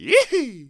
attack_1.wav